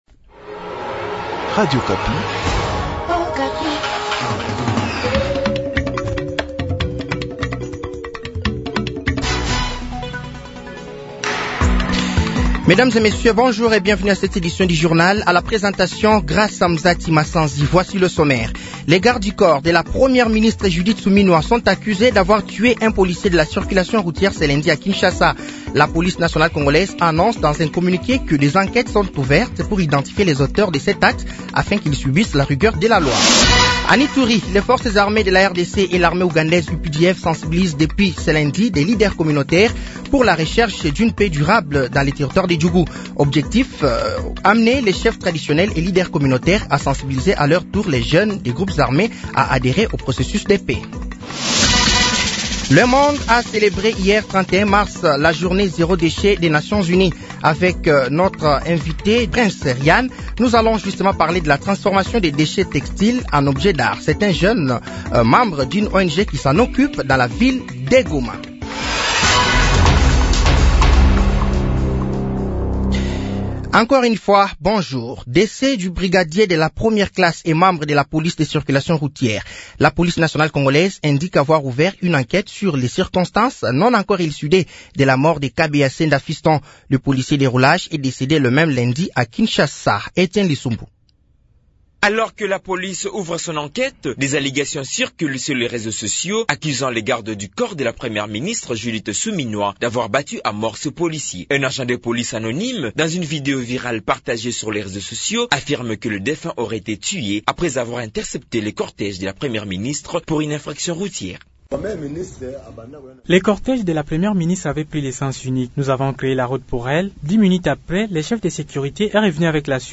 Journal de 15h
Journal français de 15h de ce mardi 01 avril 2025